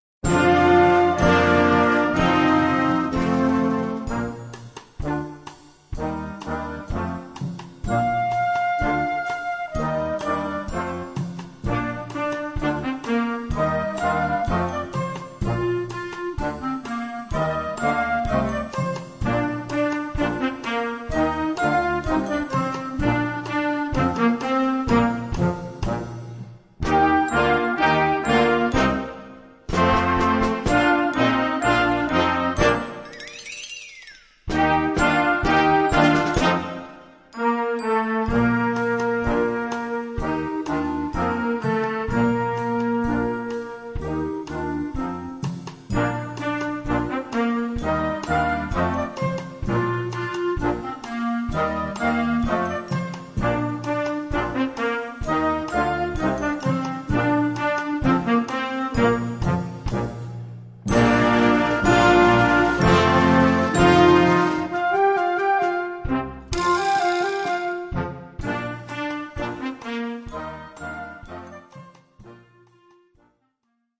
Gattung: Moderne Blasmusik
Besetzung: Blasorchester